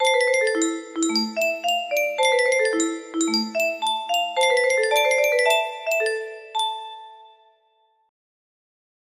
Mystery but faster music box melody